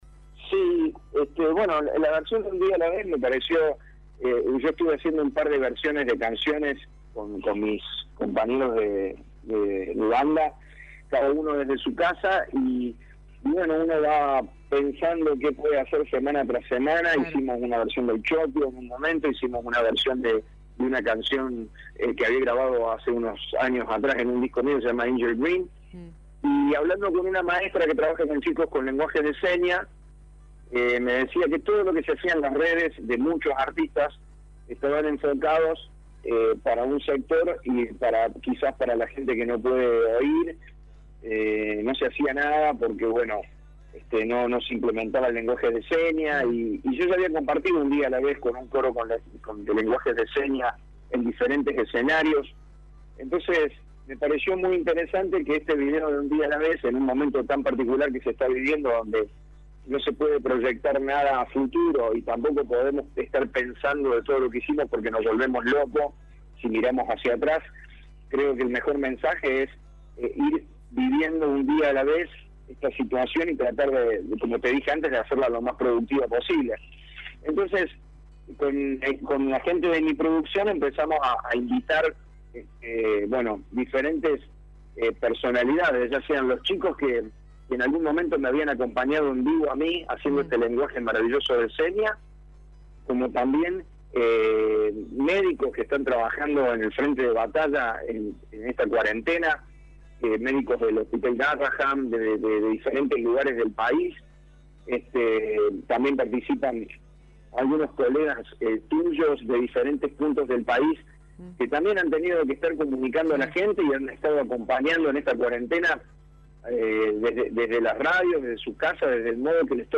lo reflejó en la entrevista con Radio Show.